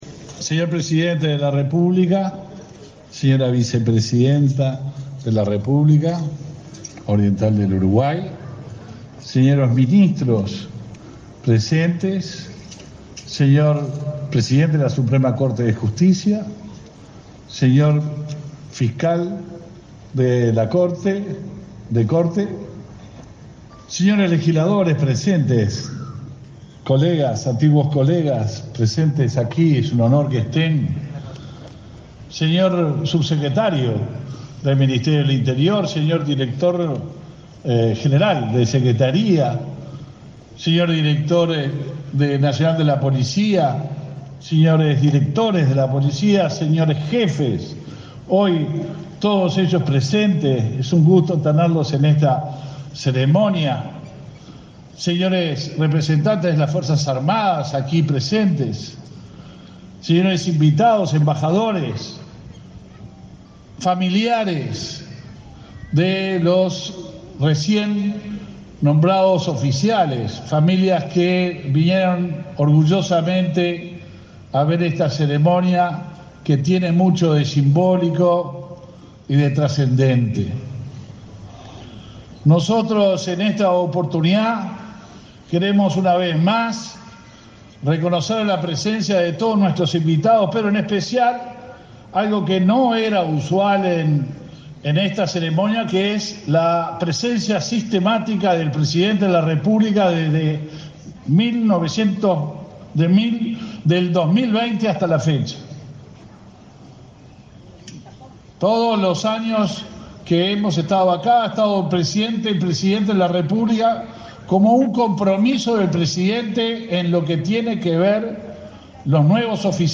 Palabras del ministro del Interior, Luis Alberto Heber, en el acto de egreso de oficiales ayudantes de la Policía Nacional
Palabras del ministro del Interior, Luis Alberto Heber, en el acto de egreso de oficiales ayudantes de la Policía Nacional 07/12/2022 Compartir Facebook X Copiar enlace WhatsApp LinkedIn Con la presencia del presidente de la República, Luis Lacalle Pou, se realizó, este 6 de diciembre, el acto por el egreso de oficiales ayudantes de la Policía Nacional.